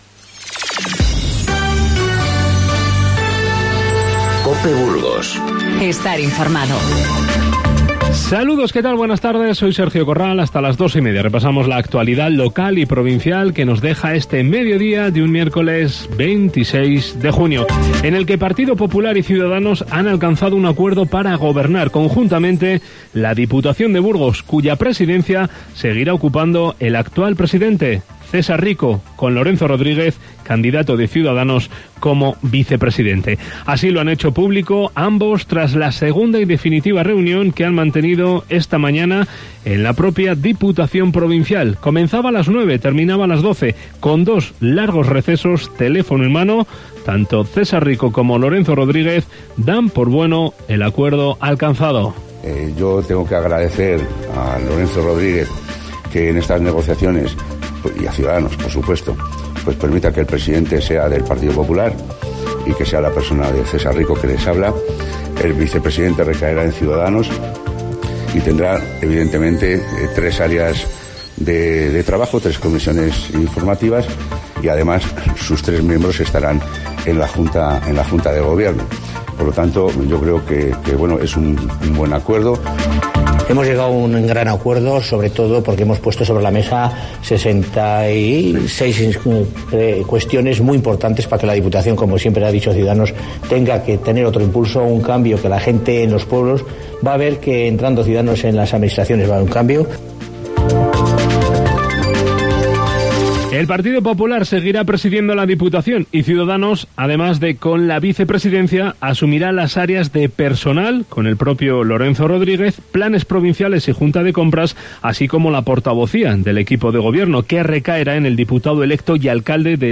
Informativo Mediodía COPE Burgos 26/06/19